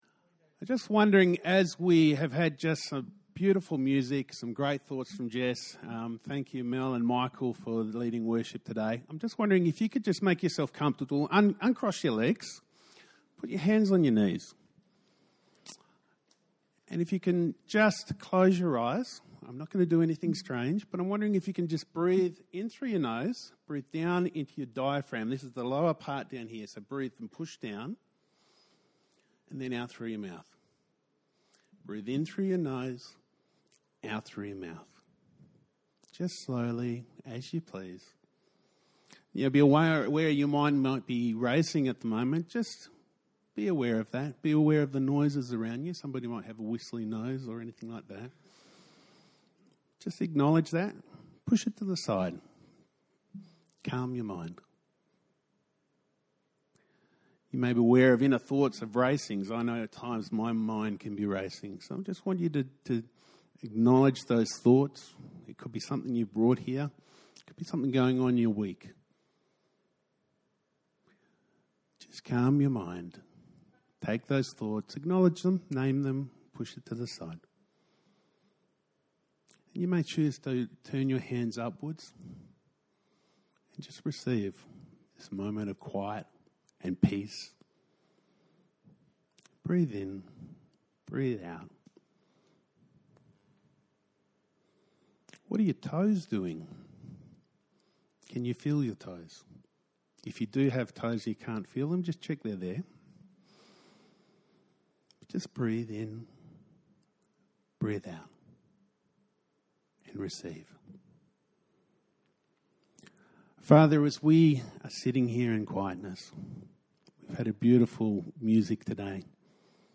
A message from the series "Aint no Grave."